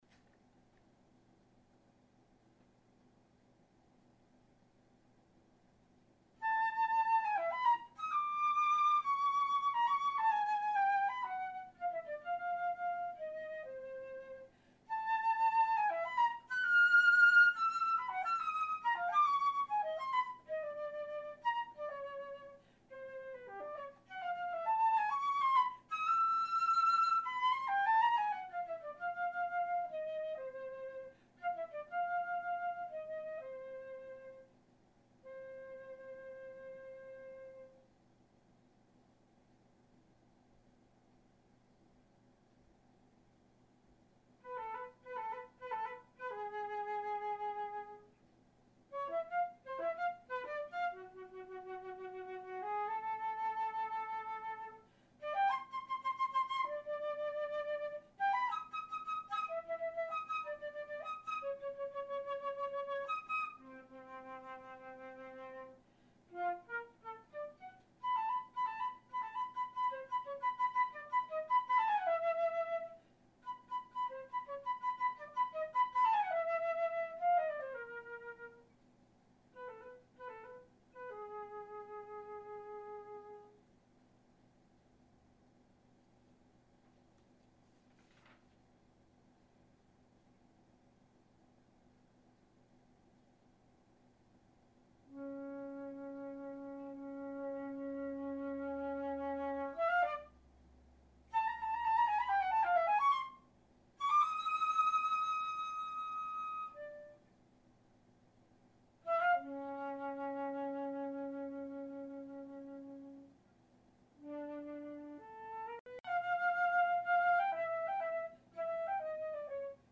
Unaccompanied Solos - no pianist needed; listed in order of increasing difficulty